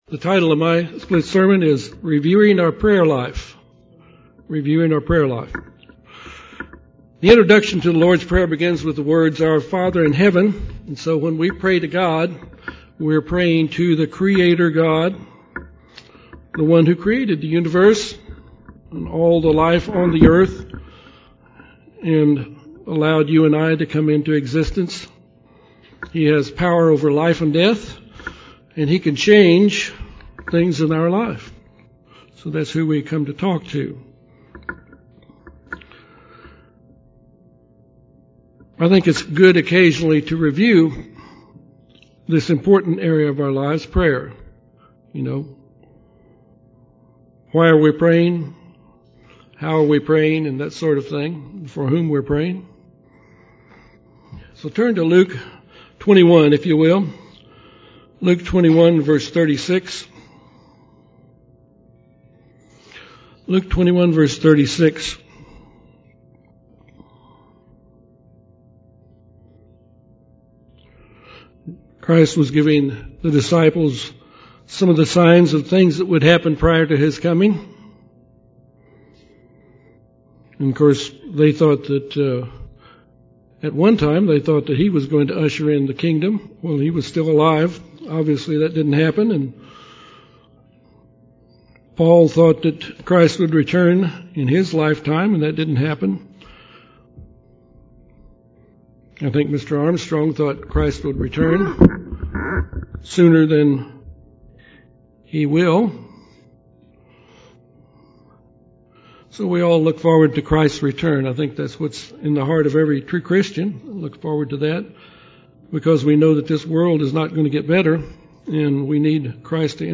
This split-sermon covers three aspects of our prayer life. How often we should pray, what our attitude should be, and what we should pray for.